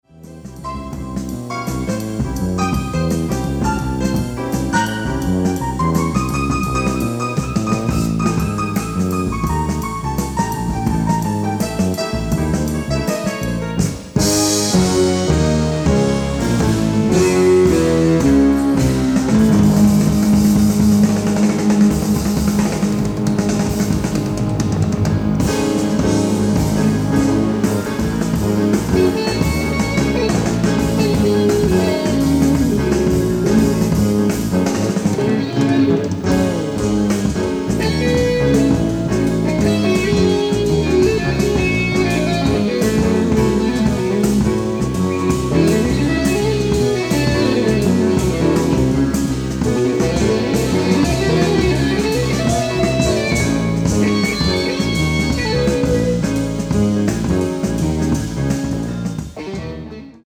piano, fender rhodes, synthesizers
fretless electric bass
drums
electric guitar